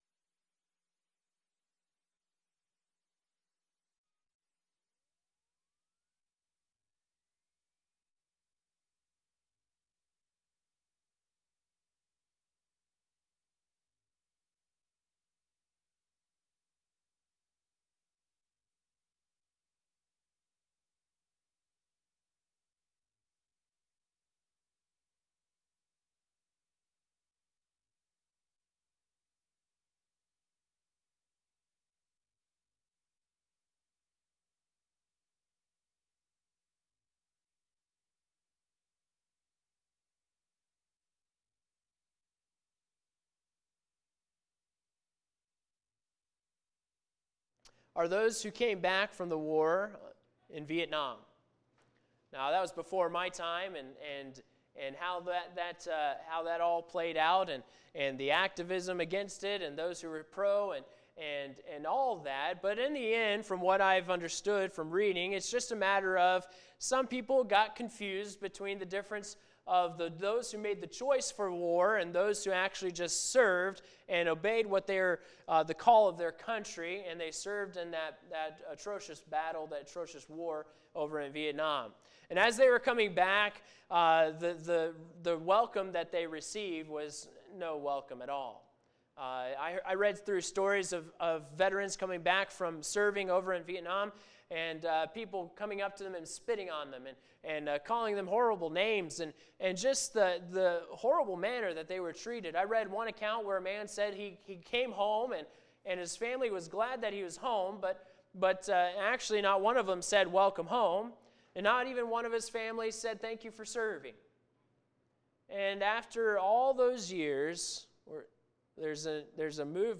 The sermon discusses the lack of recognition and acceptance Jesus Christ received when he came to Earth. It draws parallels to the lack of welcome for soldiers returning from war.